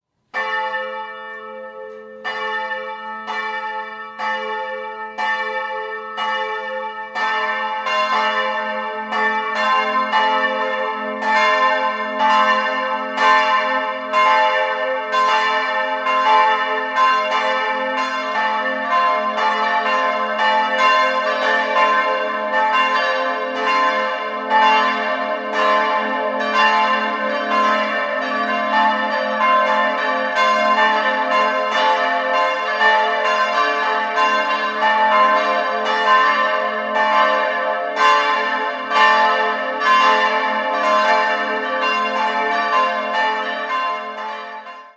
Marienglocke a'+0 485 kg 1965 Friedrich Wilhelm Schilling, Heidelberg Ägidiusglocke c''+2 287 kg 1965 Friedrich Wilhelm Schilling, Heidelberg Theresienglocke d''+0 197 kg 1965 Friedrich Wilhelm Schilling, Heidelberg